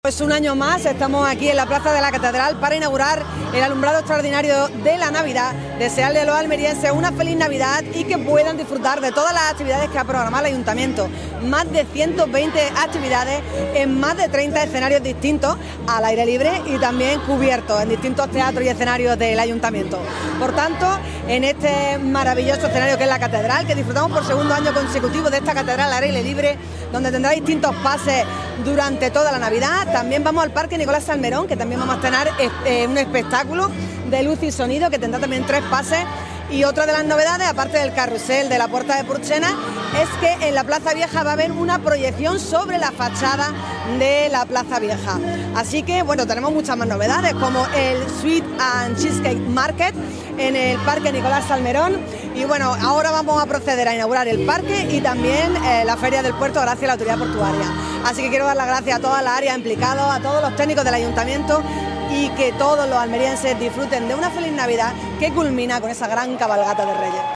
La alcaldesa, en una Plaza de la Catedral a rebosar, ha señalado que “el encendido de la iluminación ornamental nos sitúa ante la celebración de una de las fechas más esperadas del año.
ALCALDESA-ENCENDIDO-NAVIDAD.mp3